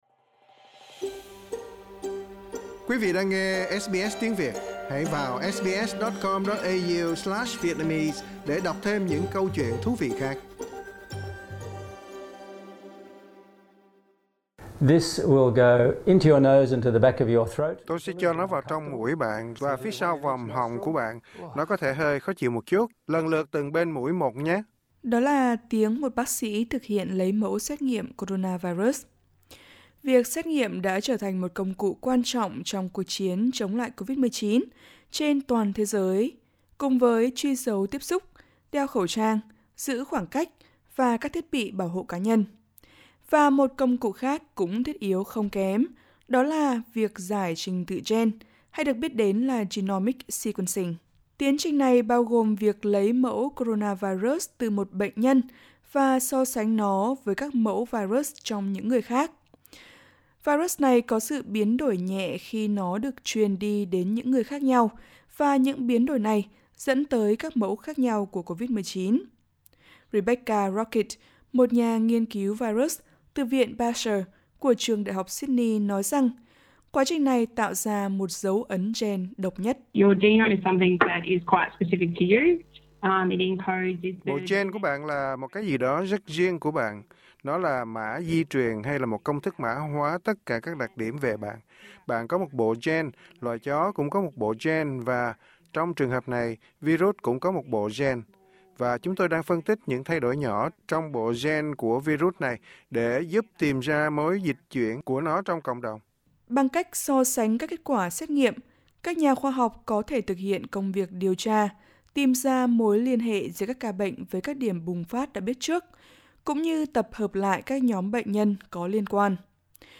Đó là tiếng một bác sỹ thực hiện lấy mẫu xét nghiệm coronavirus.